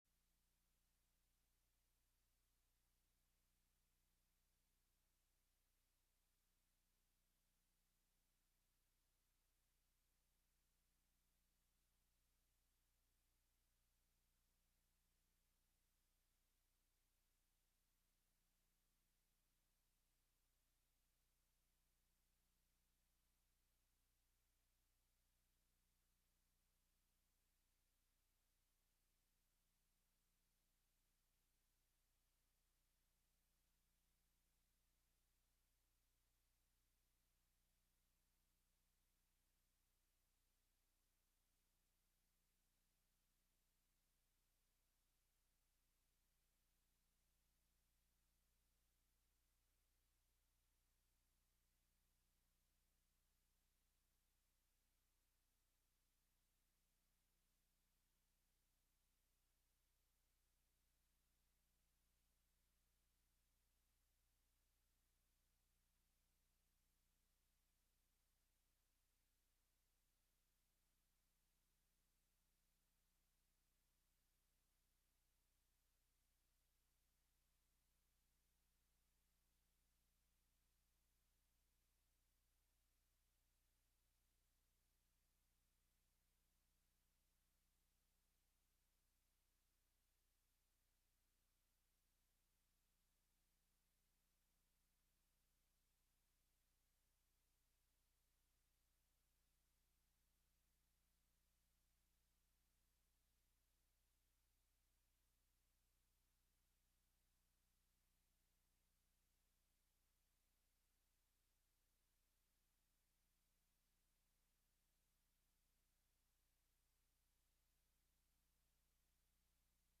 FWBC Missions Conference 2024